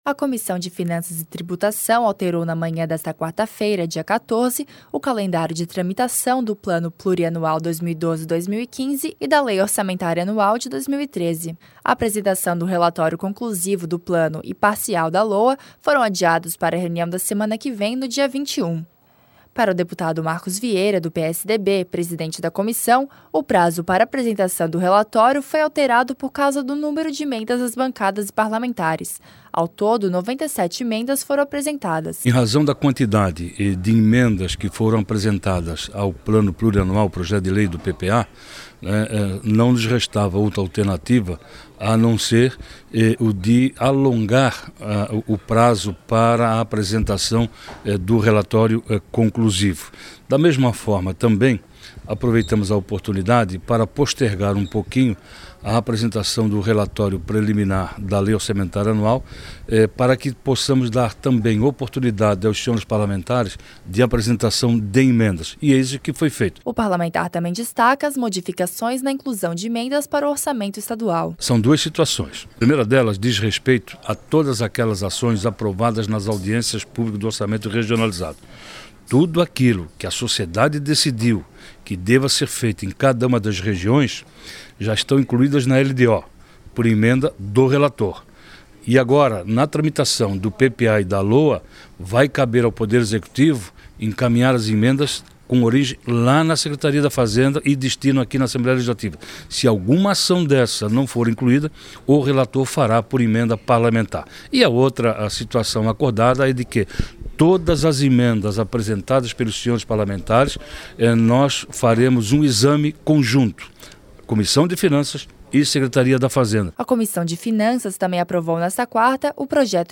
Reunião da Comissão de Finanças e Truibutação